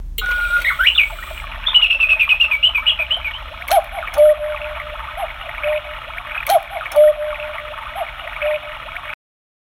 14-Cuckoo-Tune.mp3